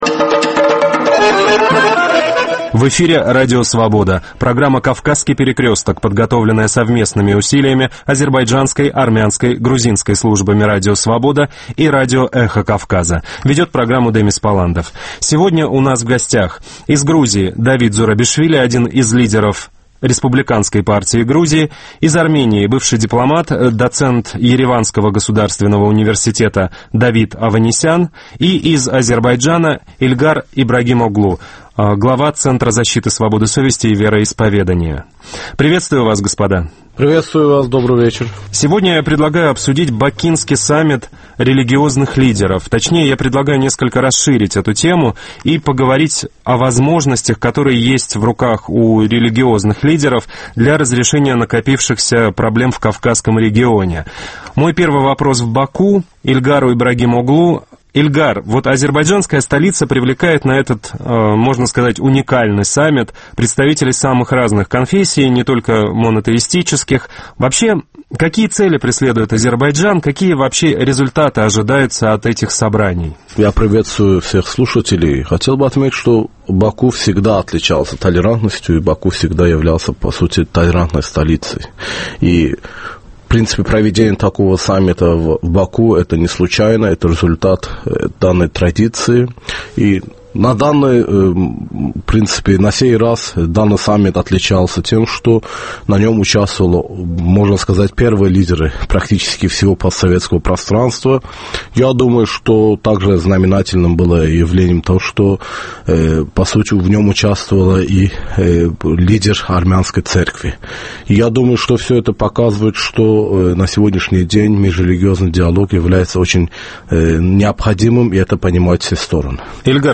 Dəyirmi masa: din xadimlərinin Bakı sammiti